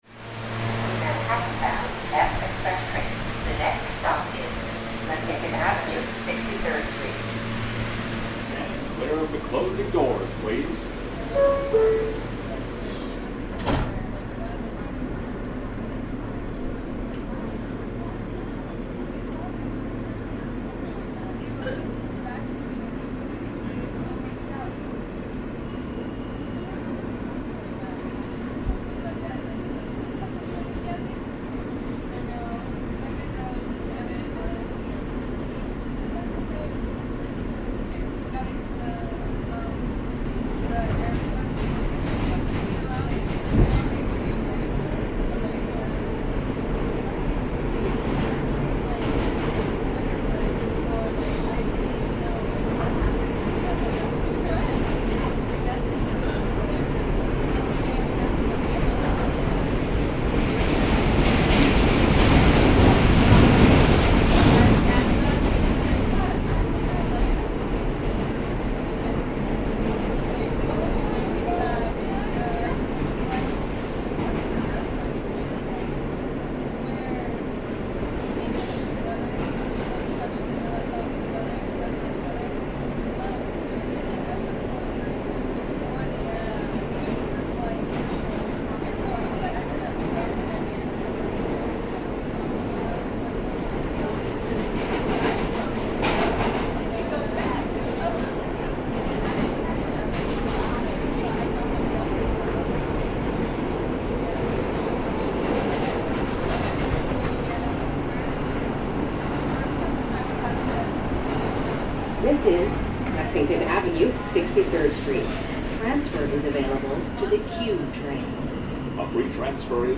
On the New York metro, we take a trip from Roosevelt Island to Bryant Park in Manhattan.